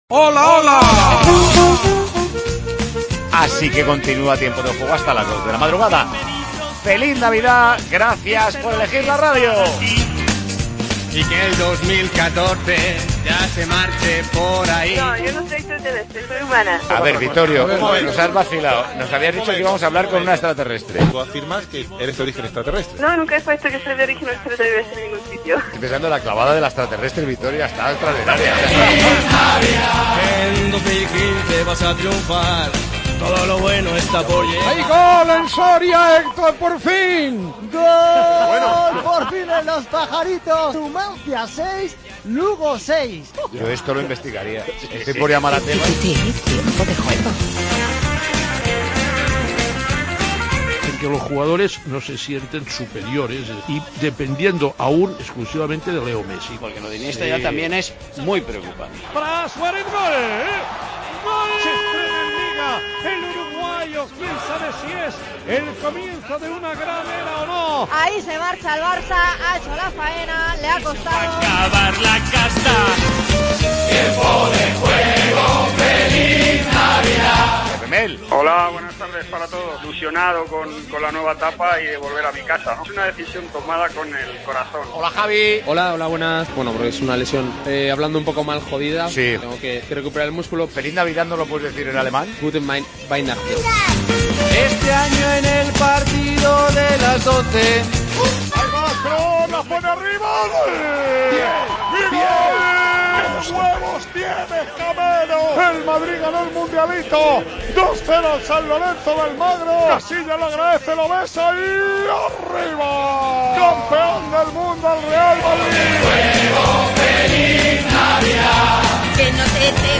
Con el Villancico de Deportes COPE de fondo, aconsejamos regalos para las Navidades, escuchamos la victoria del Real Madrid en el Mundialito, los gazapos de la redacción y mucho más.
Con Paco González, Manolo Lama y Juanma Castaño